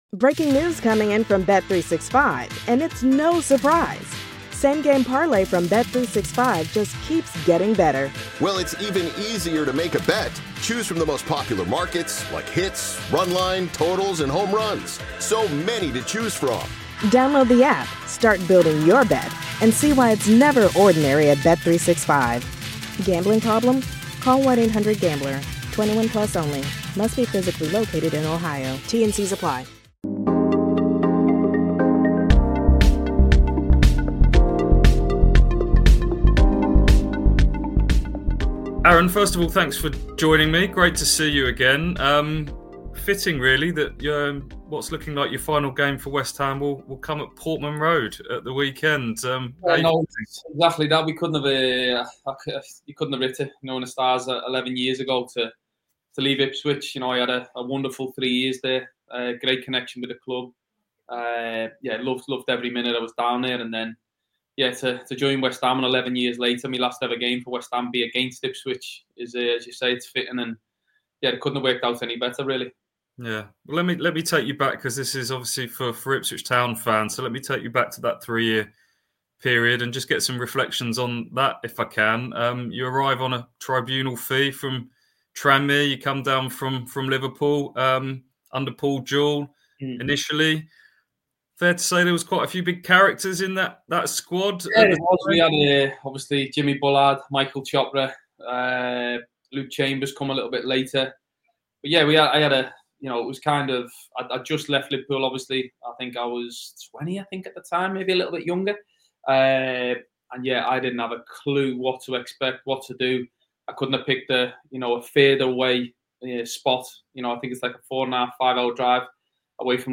KOA exclusive interview: Aaron Cresswell - West Ham farewell and what’s next?